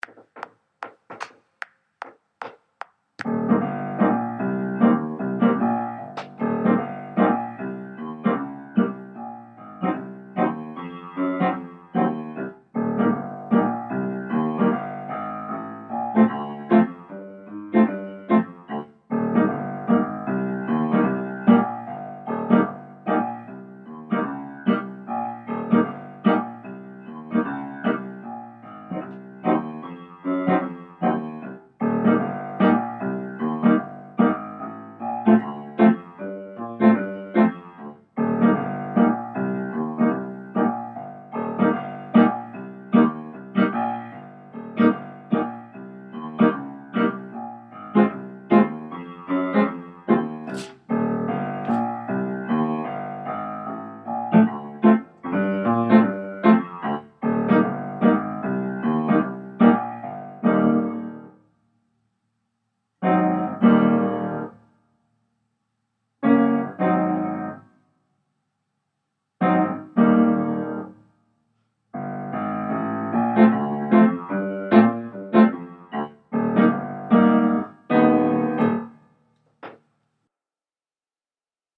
Riff Laden Piano Only